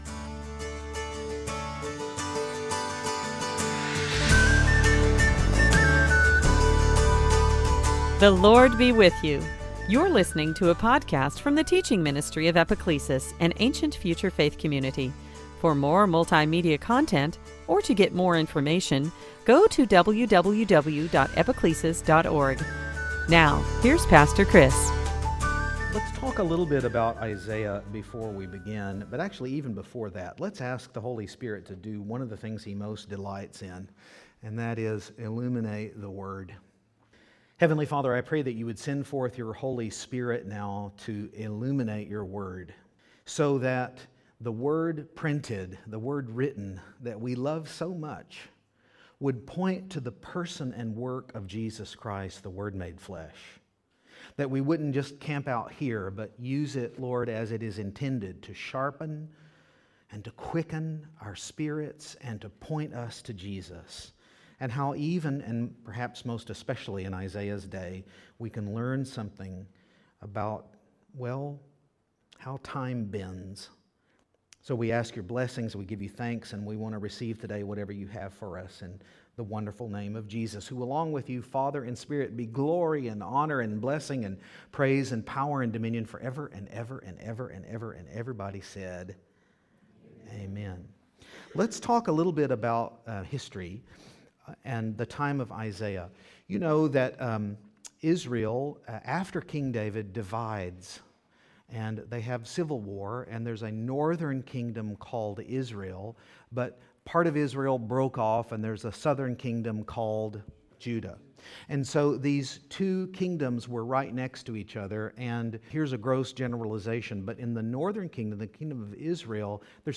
2016 Sunday Teaching Advent Isaiah Leonard Bernstein longing Malcomb Guite tension Advent